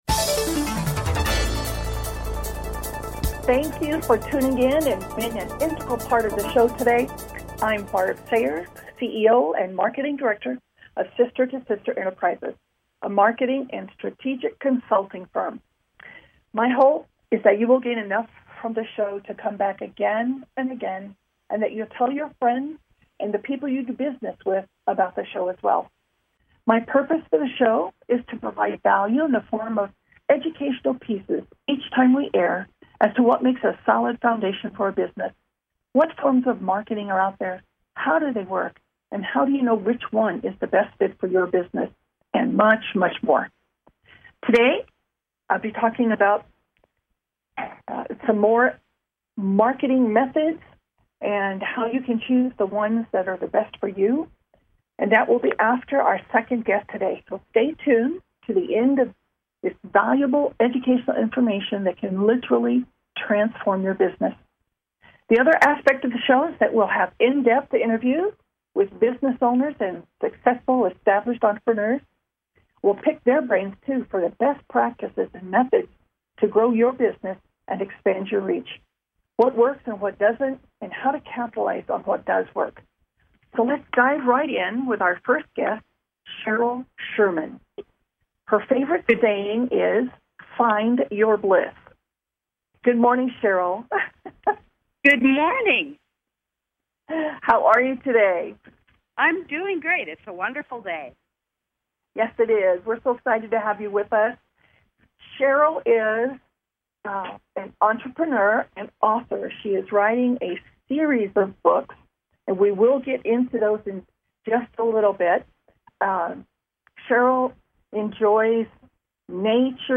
Talk Show Episode
Call-ins encouraged!